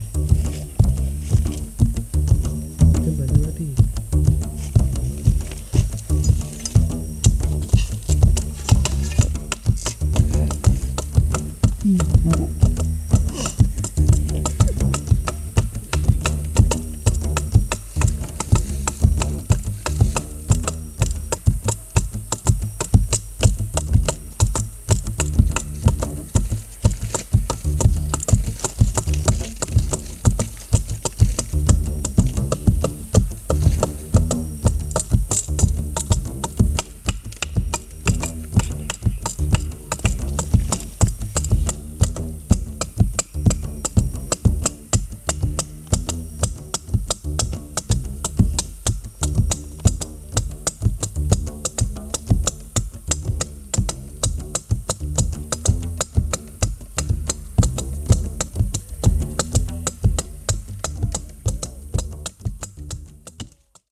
Bewegender Gesang und das packende Trommeln der Bayaka Pygmäen
Soundscape Series by Gruenrekorder